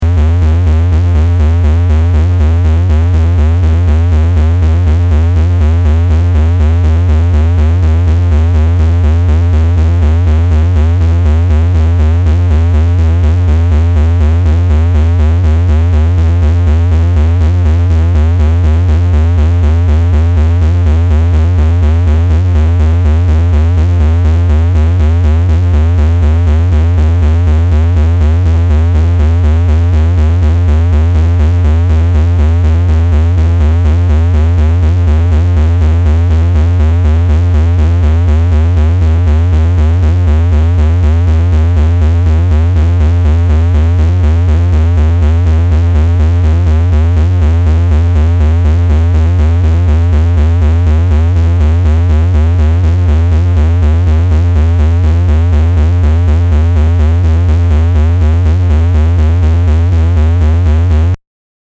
Subtle small pieces of noise. Made only with No Input Mixer in 2004.